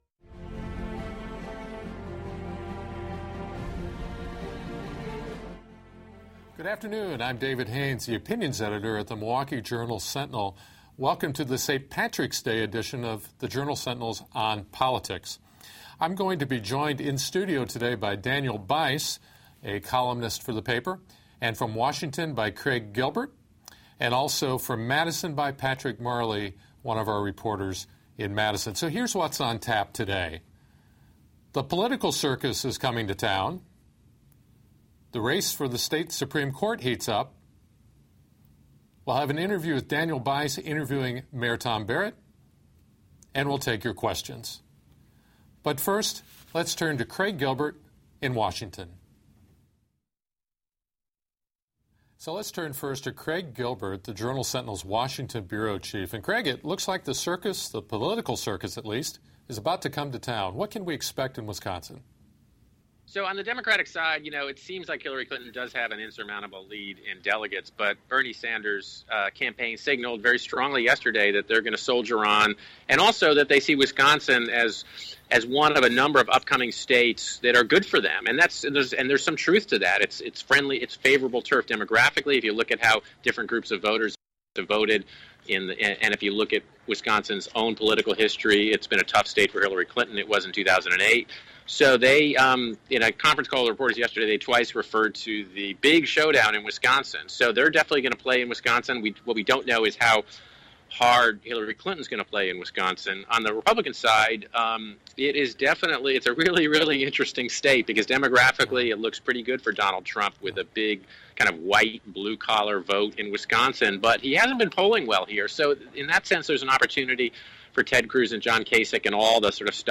interviews Mayor Barrett; Obama makes his Supreme Court nomination